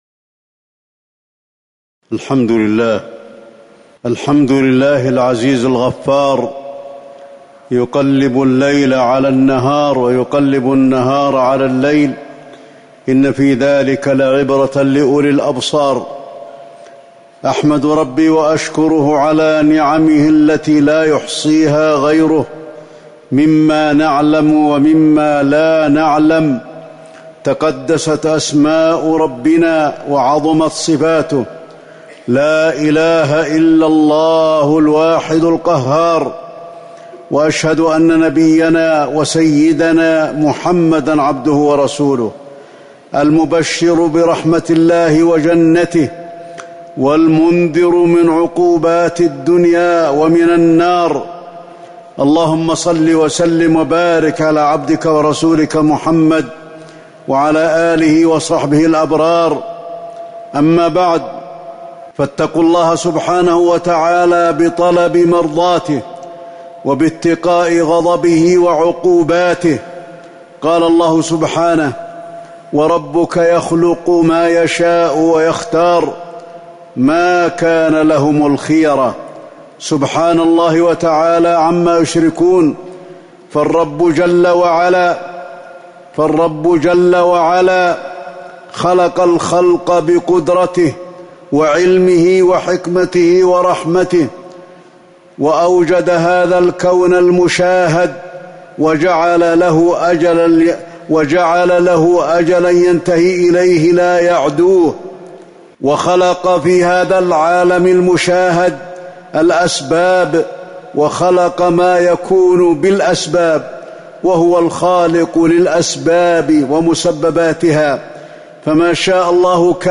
تاريخ النشر ١٨ شوال ١٤٤٠ هـ المكان: المسجد النبوي الشيخ: فضيلة الشيخ د. علي بن عبدالرحمن الحذيفي فضيلة الشيخ د. علي بن عبدالرحمن الحذيفي كرم الله ونعمه على عبده